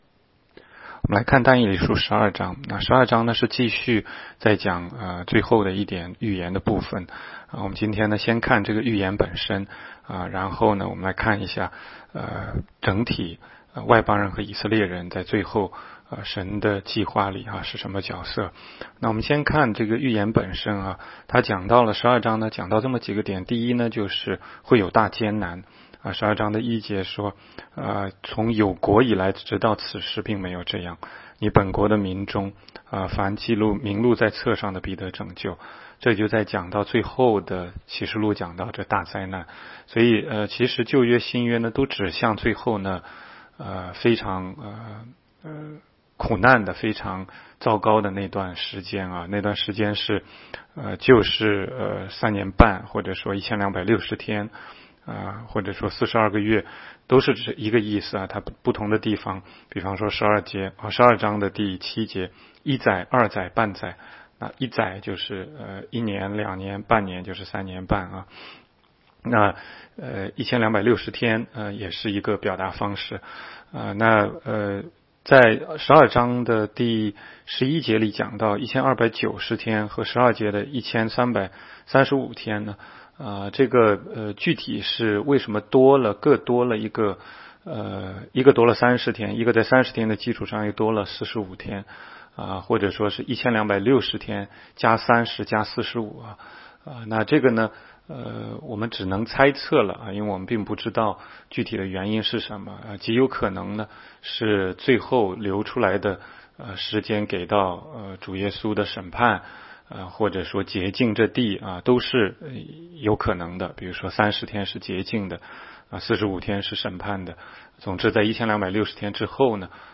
16街讲道录音 - 每日读经 -《但以理书》12章